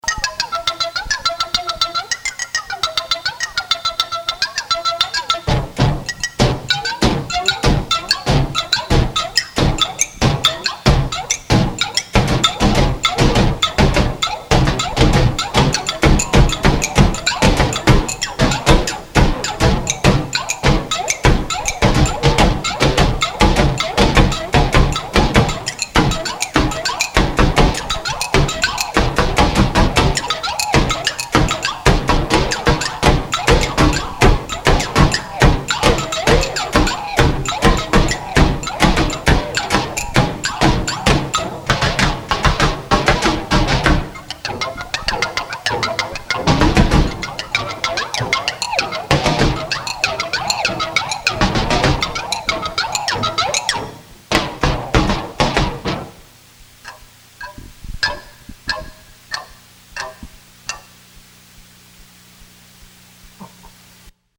Recorded live at home in Manhattan.
alto clarinet, vocals, fx
music stand, glass, vocals,Â fx
Stereo (722 / Pro Tools)